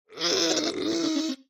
Minecraft Version Minecraft Version 1.21.5 Latest Release | Latest Snapshot 1.21.5 / assets / minecraft / sounds / mob / strider / retreat5.ogg Compare With Compare With Latest Release | Latest Snapshot
retreat5.ogg